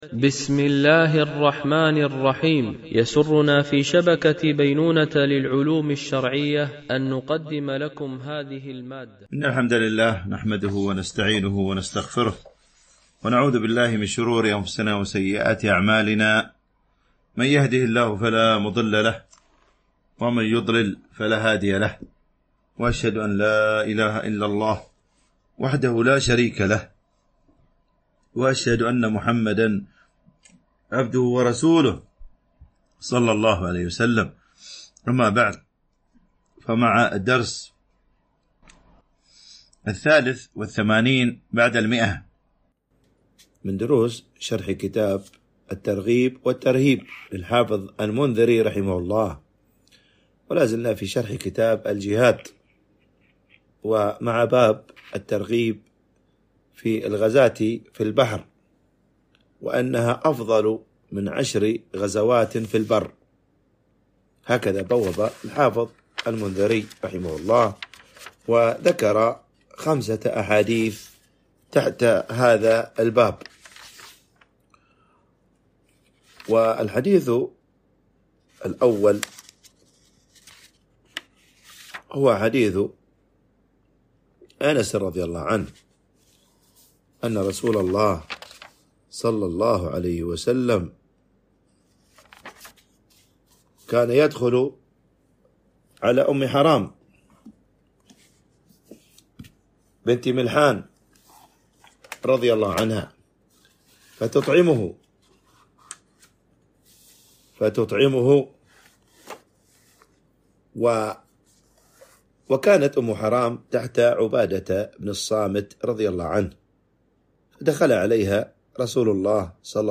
) الألبوم: شبكة بينونة للعلوم الشرعية التتبع: 183 المدة: 38:16 دقائق (17.53 م.بايت) التنسيق: MP3 Mono 44kHz 64Kbps (CBR)